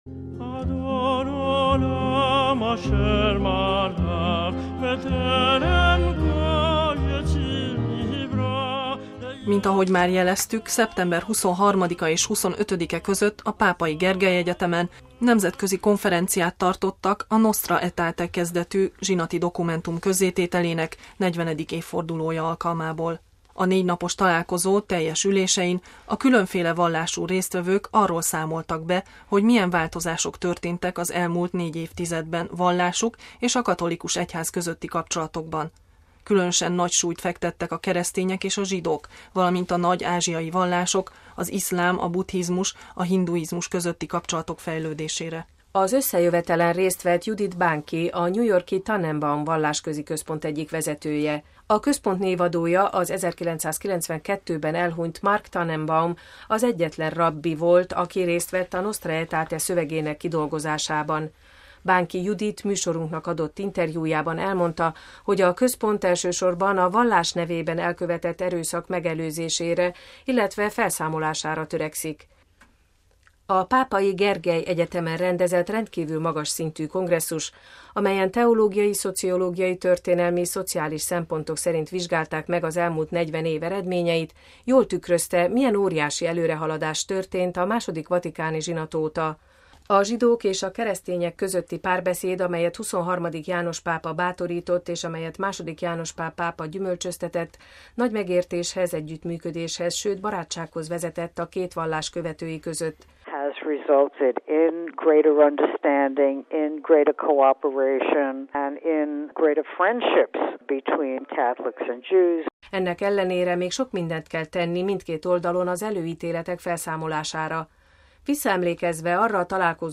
Hedra asszuáni érsek műsorunknak adott nyilatkozatában elmondta, hogy mély benyomást tett rá a Szentatyával való találkozás.